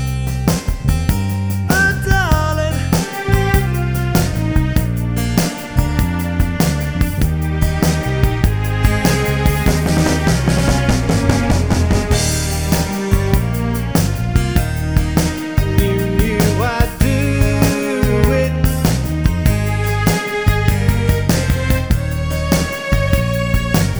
Minus Piano Rock 'n' Roll 4:01 Buy £1.50